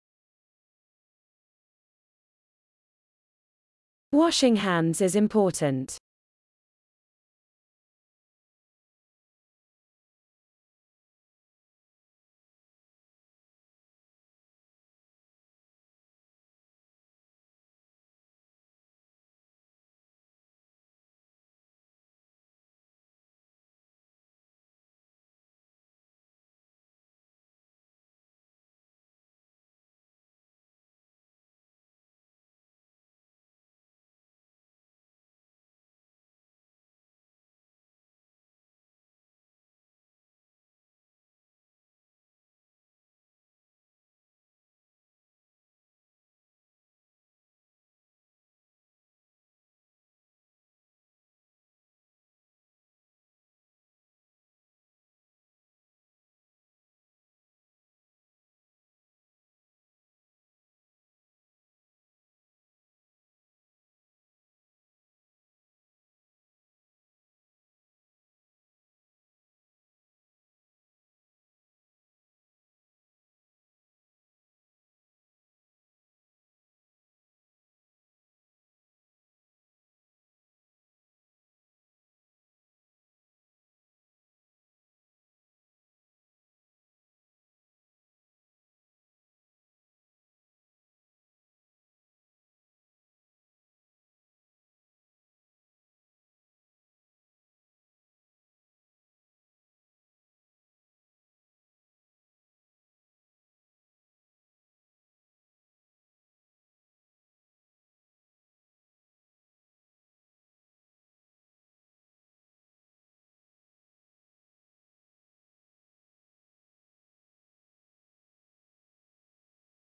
Speakers: